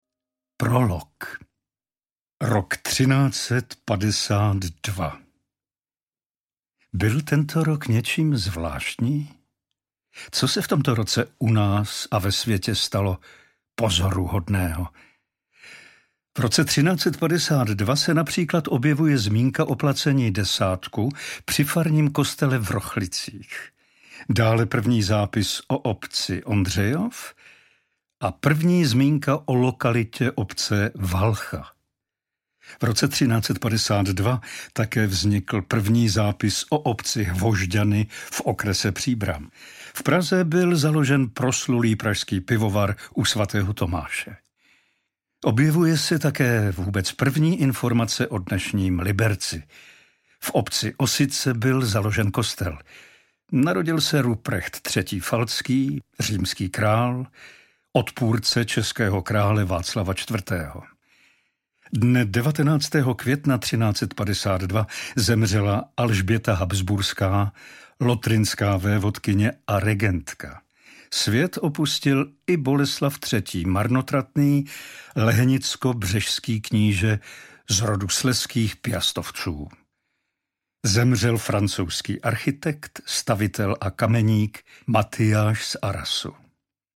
Nechte se unést audioknižním vydáním knihy Mlýn. Čte Pavel Soukup.